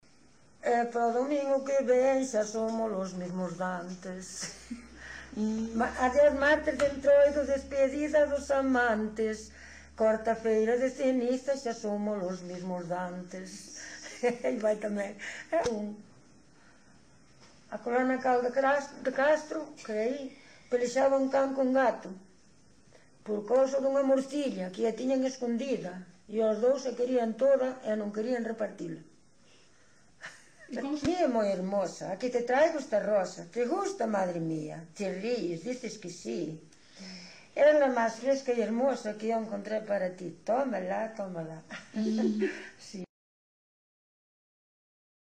Coplas narrativa
Tipo de rexistro: Musical
Áreas de coñecemento: LITERATURA E DITOS POPULARES > Coplas
Lugar de compilación: Vila de Cruces - Sabrexo (Santa María) - Sabrexo
Soporte orixinal: Casete
Datos musicais Refrán
Instrumentación: Voz
Instrumentos: Voz feminina